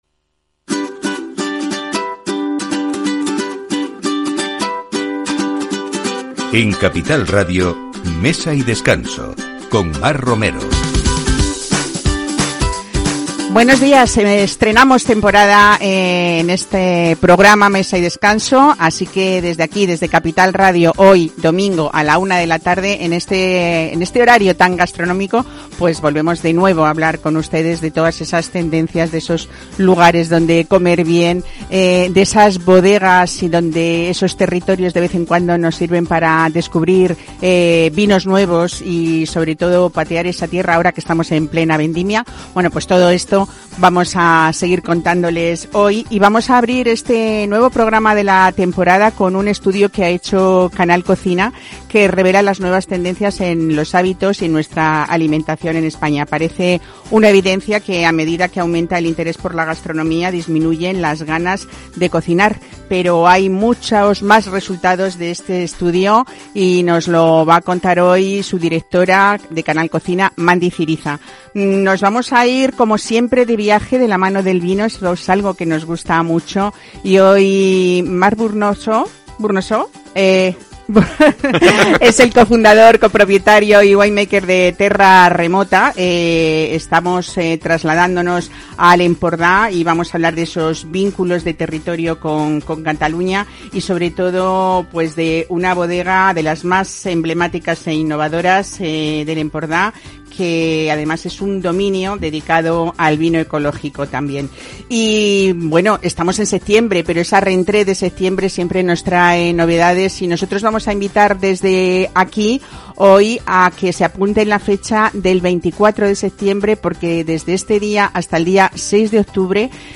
Programa dedicado a la actualidad gastronómica y al mundo del vino, el enoturismo y lugares de interés para disfrutar. Expertos en enología y destacados sumilleres se alternan cada semana en un espacio para conocer los mejores vinos aconsejados por los mejores especialistas. El repaso a la actualidad gastronómica con profesionales de la hostelería, cocineros y restauradores.